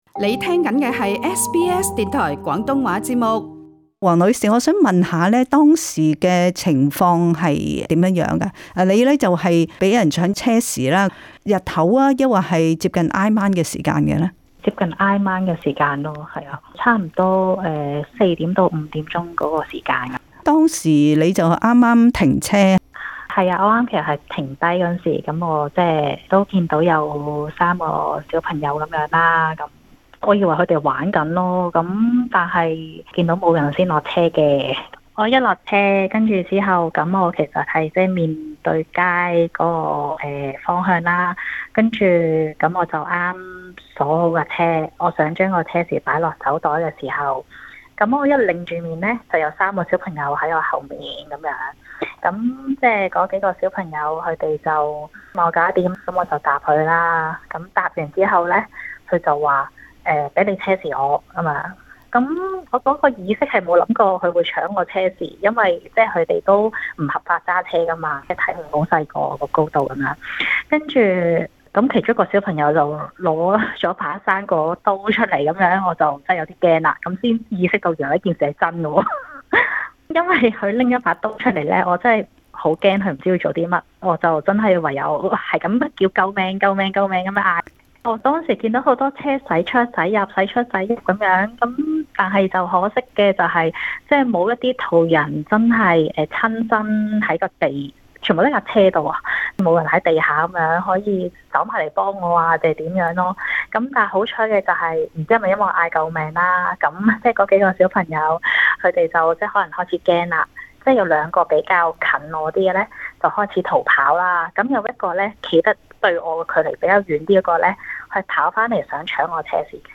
在今次的【社區專訪】